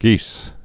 (gēs)